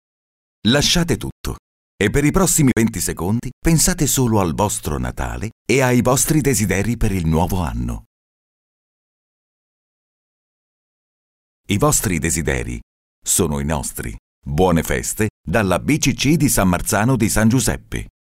Kein Dialekt
Sprechproben: